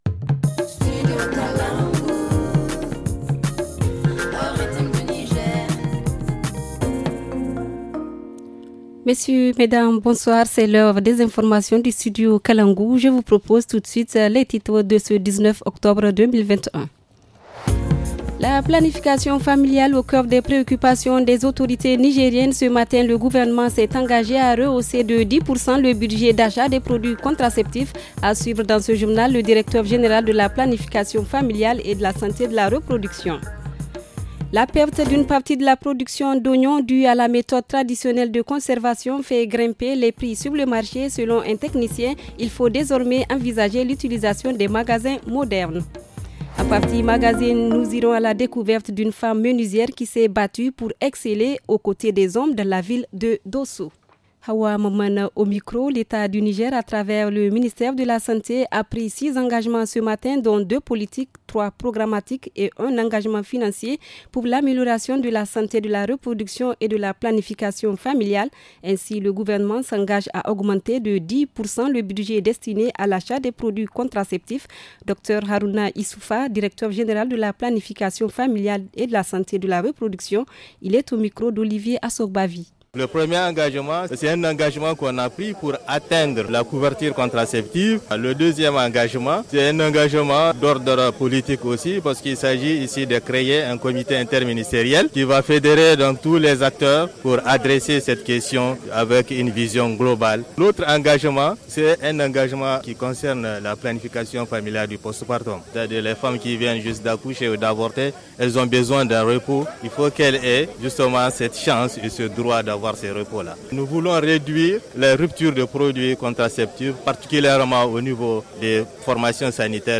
Le journal du 19 octobre 2021 - Studio Kalangou - Au rythme du Niger